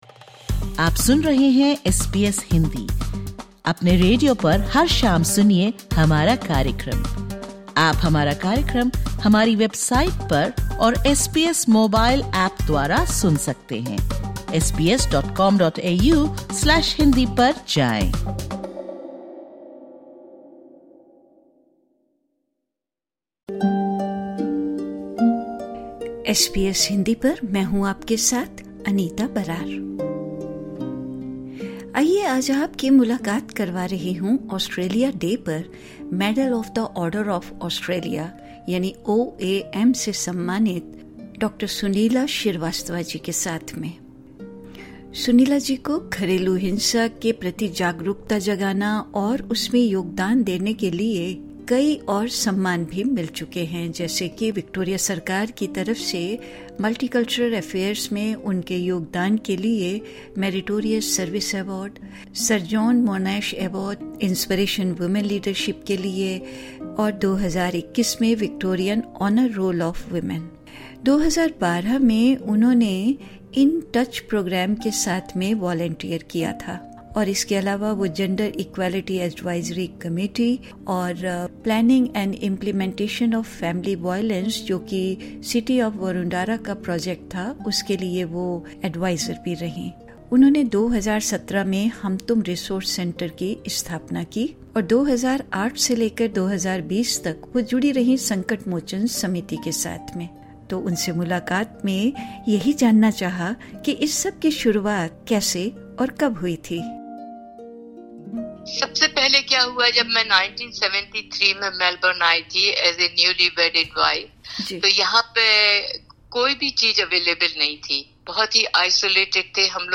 एस बी एस हिन्दी से बातचीत करते हुये इस पॉडकास्ट में उन्होंने पारिवारिक हिंसा के लिए बनी 'हम तुम टास्क फोर्स' जैसी योजनाओं और कार्यान्वयन के बारे में जानकारी दी।